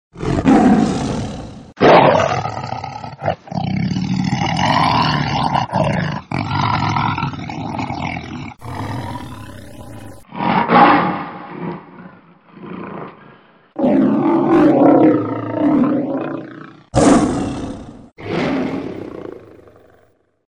Звуки рычания льва
Стереозвук рычания льва